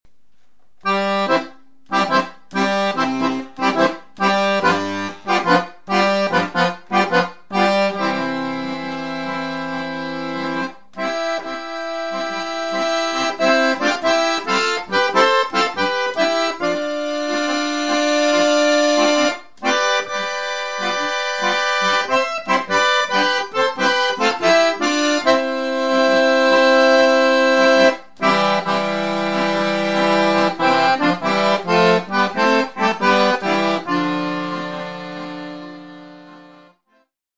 Professional Full Size Accordion, Musette, New